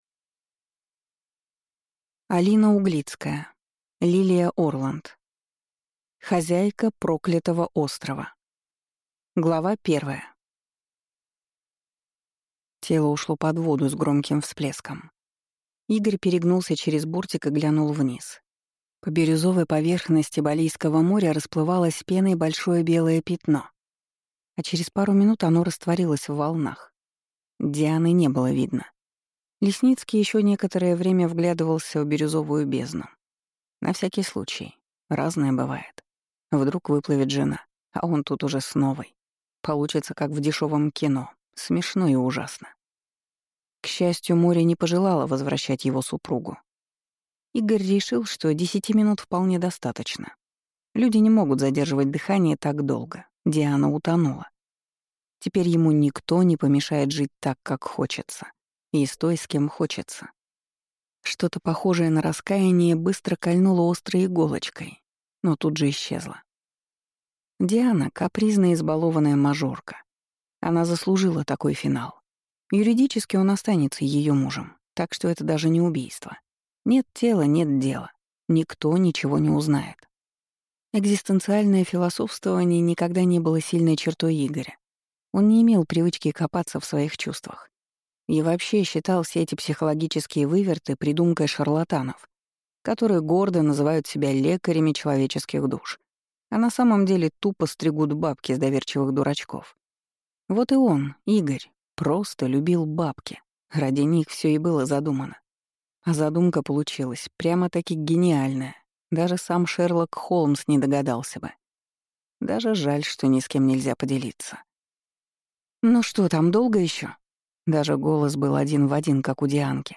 Аудиокнига Хозяйка проклятого острова | Библиотека аудиокниг
Прослушать и бесплатно скачать фрагмент аудиокниги